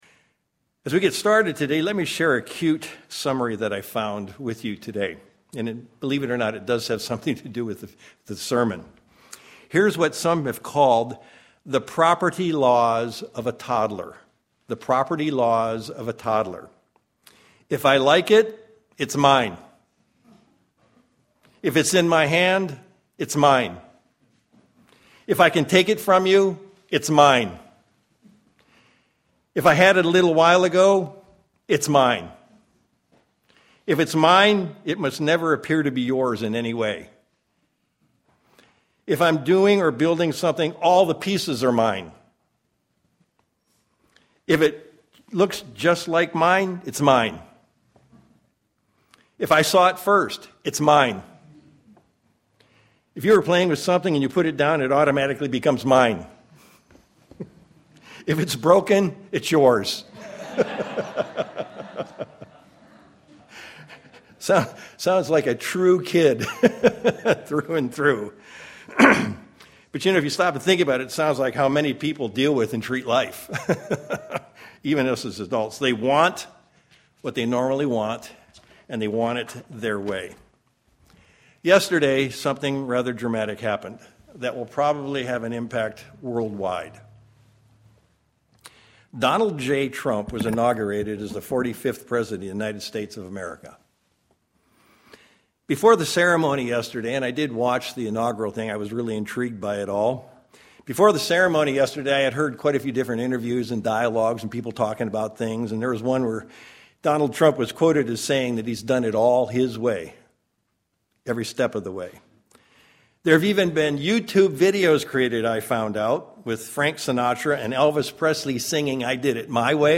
Given in Sacramento, CA
The Bible is pretty clear on the subject. sermon Studying the bible?